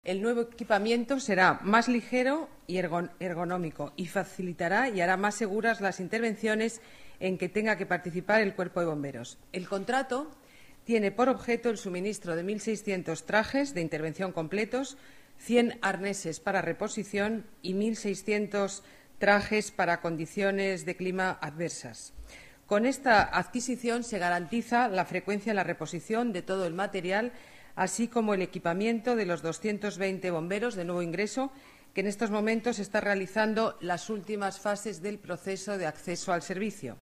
Nueva ventana:Declaraciones alcaldesa de Madrid, Ana Botella: nueva equipación bomberos